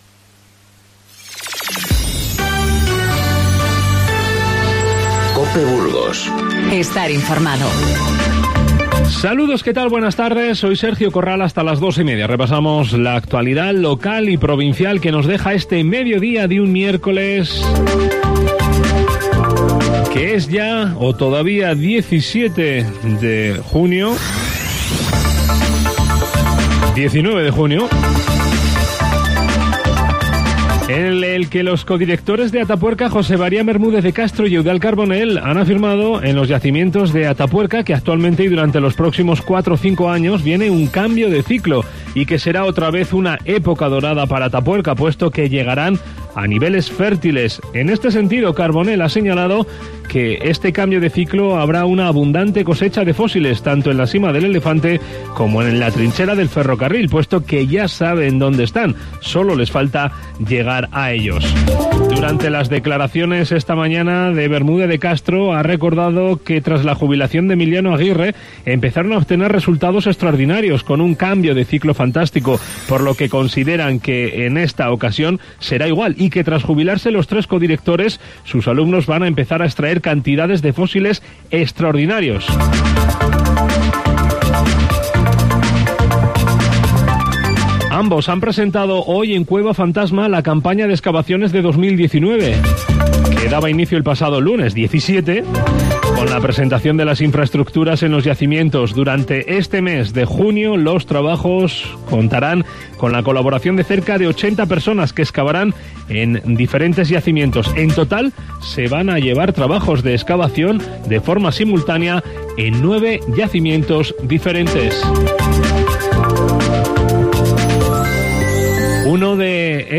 Informativo Mediodía COPE Burgos 19/06/19